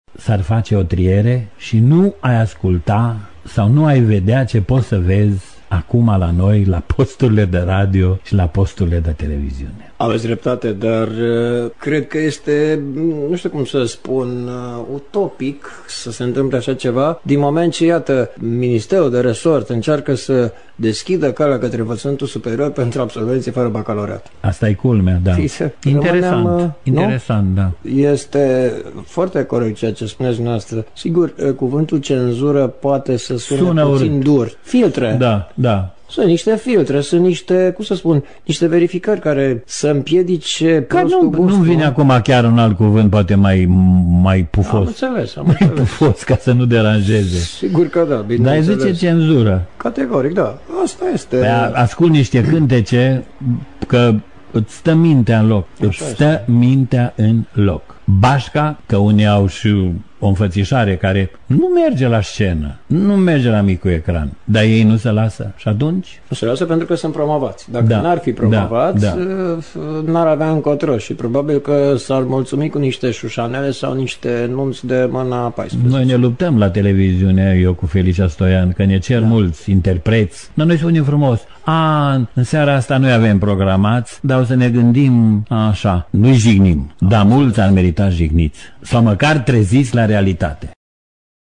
Un dialog despre debutul şi consacrarea sa în lumea bună a muzicii populare, dar şi despre activitatea actuală de realizator TV!